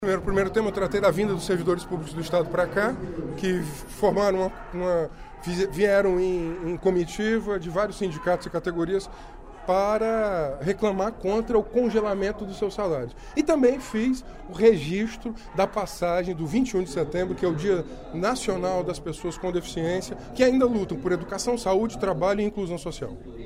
O deputado Renato Roseno (Psol) destacou, durante o primeiro expediente da sessão plenária desta terça-feira (22/09), a presença de representantes do Fórum Unificado das Associações e Sindicatos dos Servidores Públicos Estaduais do Ceará (Fuaspec) nas galerias da Assembleia Legislativa.